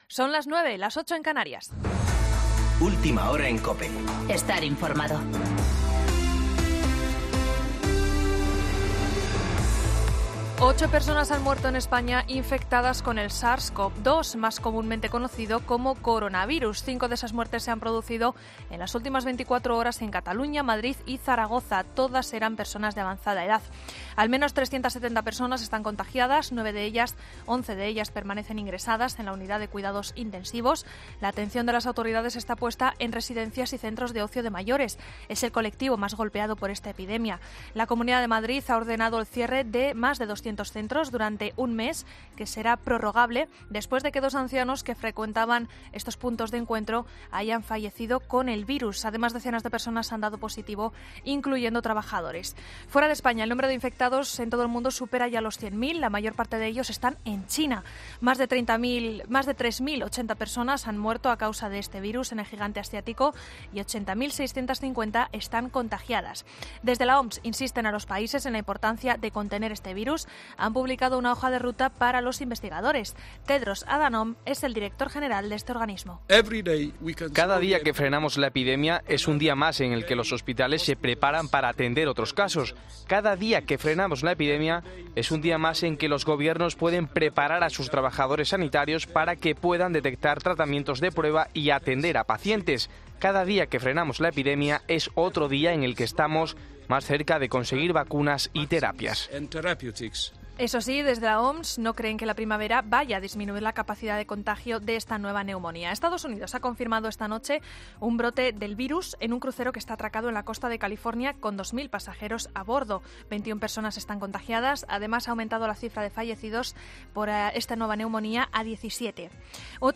Boletín de noticias COPE del 7 de marzo a las 9.00